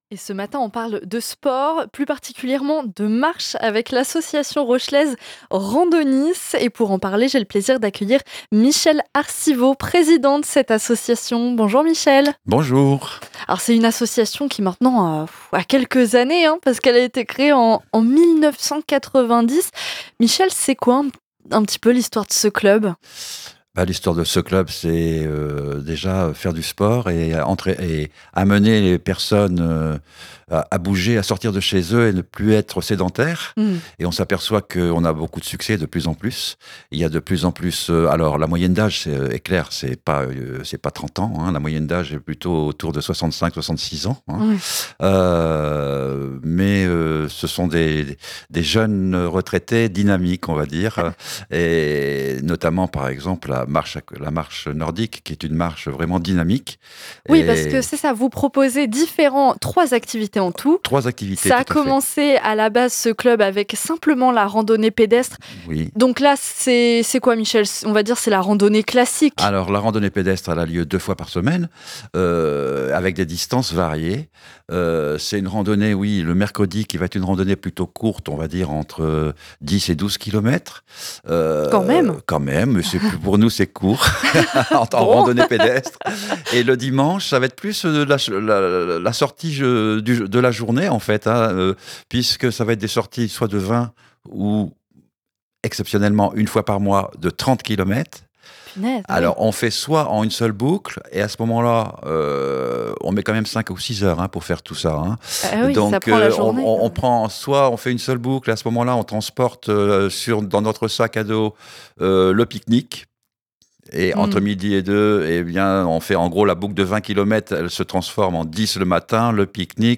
Dans La Vague Matinale, nous avons eu le plaisir de recevoir l’association RAND’AUNIS.